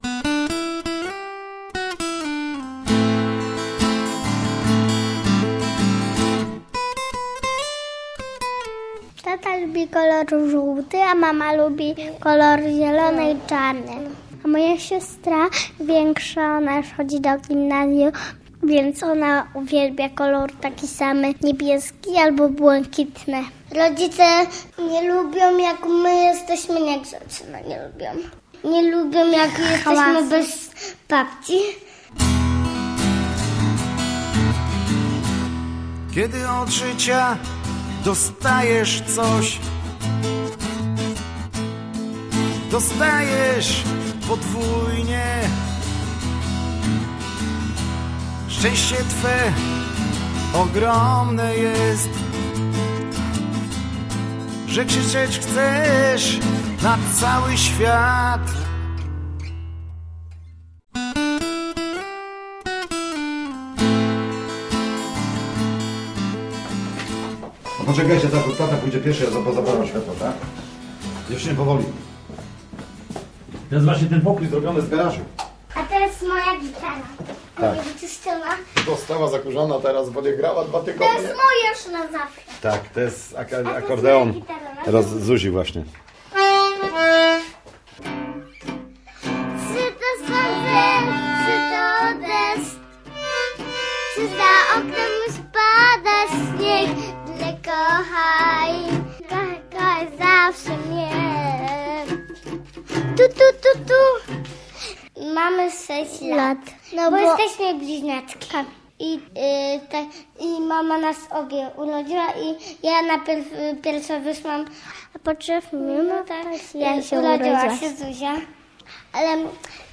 Chciałem być jak ojciec - reportaż